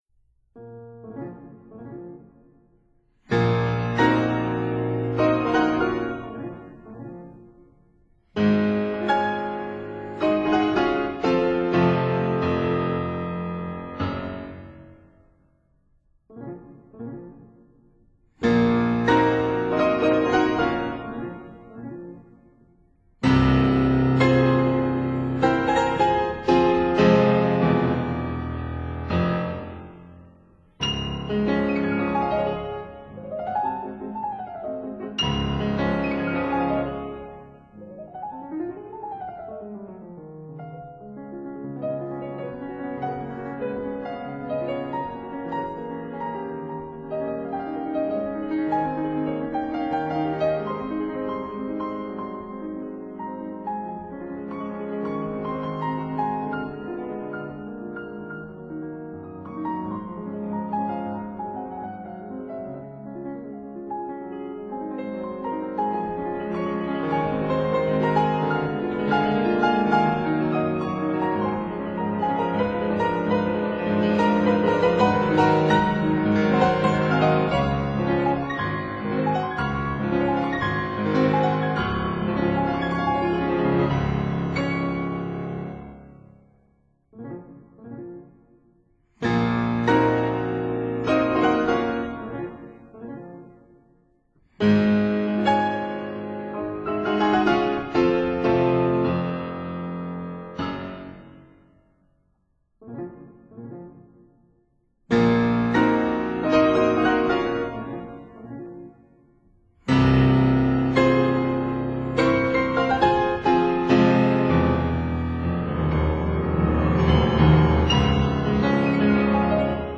韓國鋼琴家。
piano